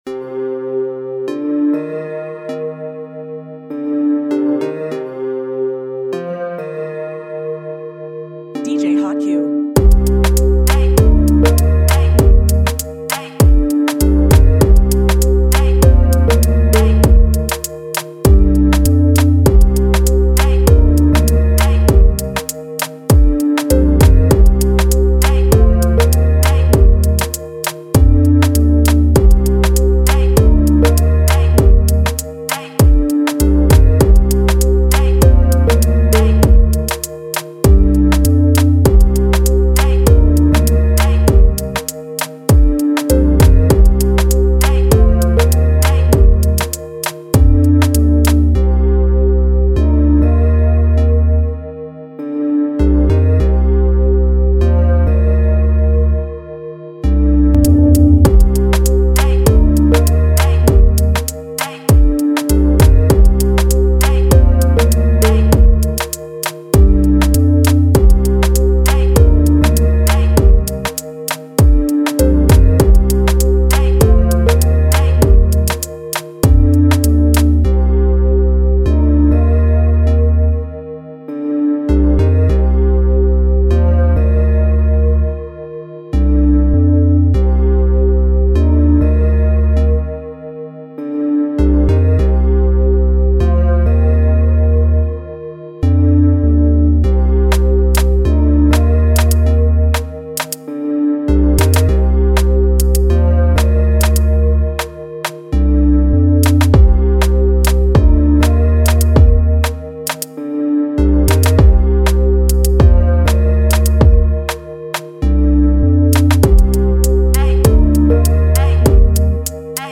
A dope Caribbean vibe track to start your summer of right!